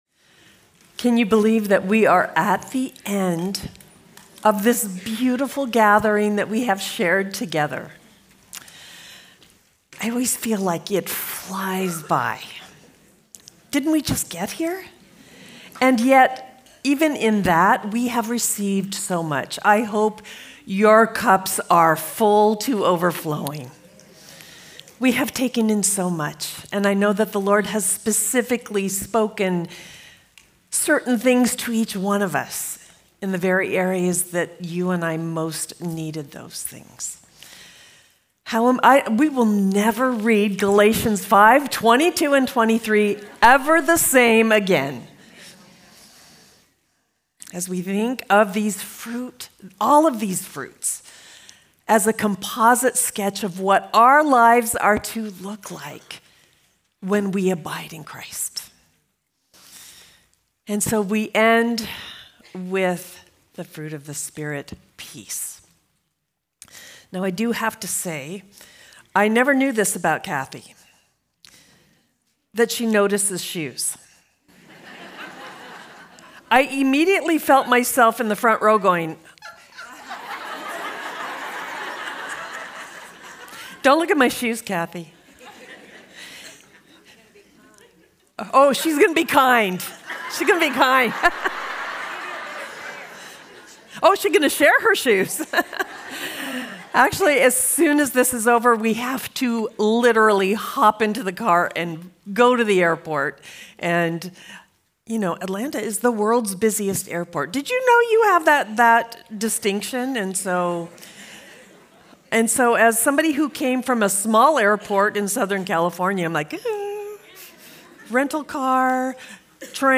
Home » Sermons » Fruit of Peace
2026 DSWG Conference: Women's Gathering Date